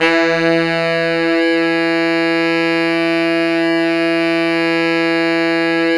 SAX SFE3X 0D.wav